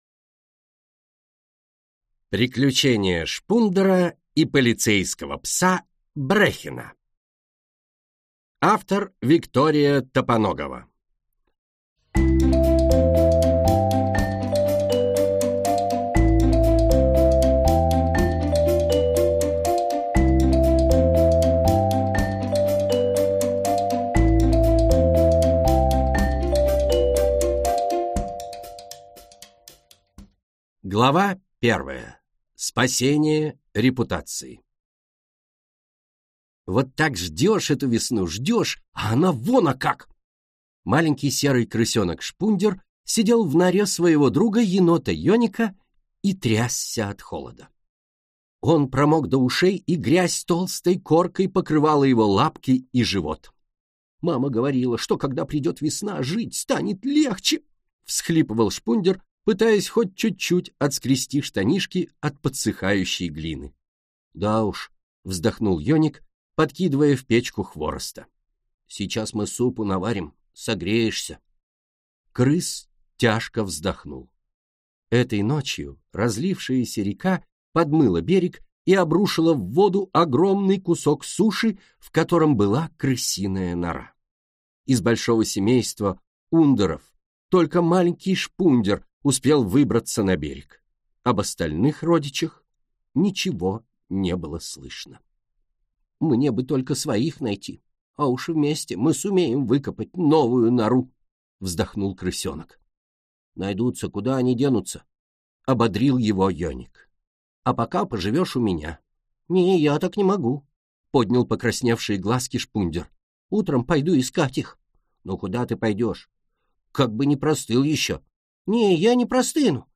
Аудиокнига Приключения Шпундера и полицейского пса Брехена | Библиотека аудиокниг